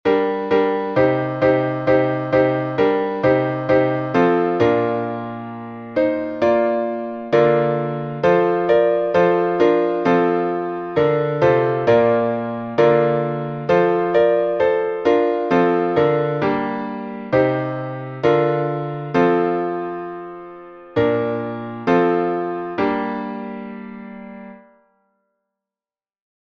Знаменный распев, глас 8